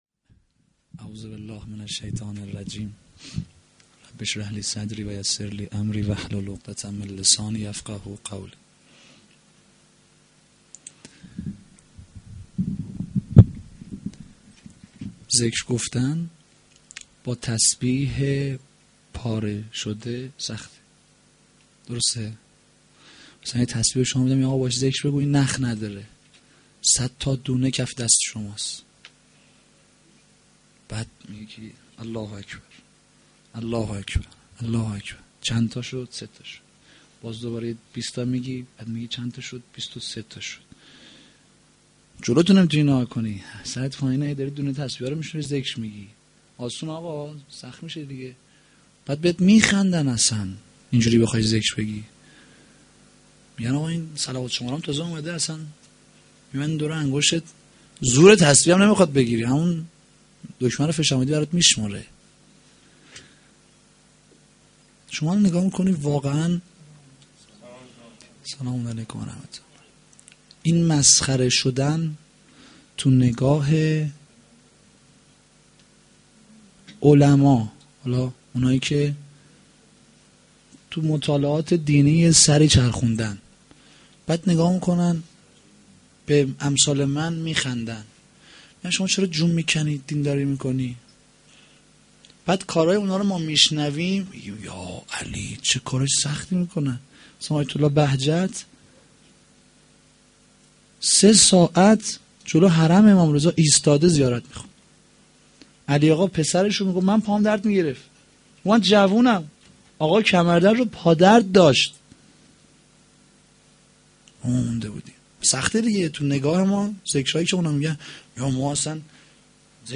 fa-dovom-93-sh1-sokhanrani.mp3